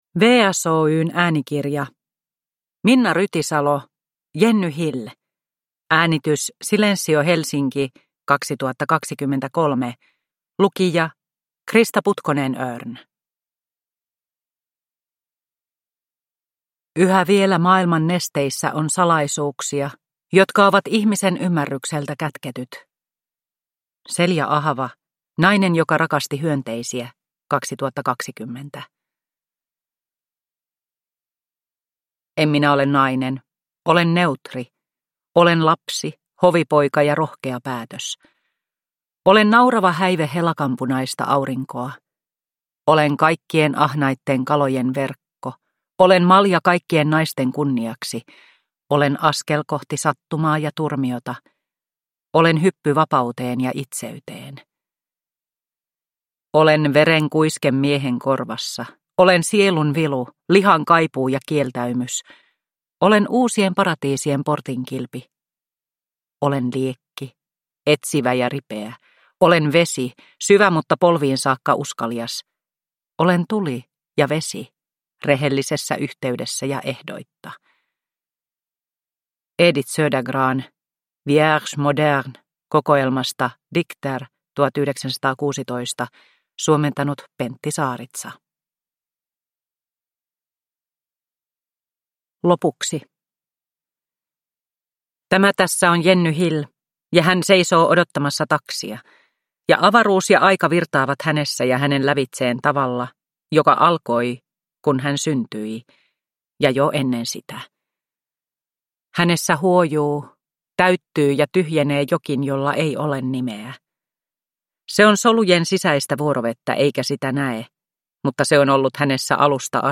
Jenny Hill – Ljudbok – Laddas ner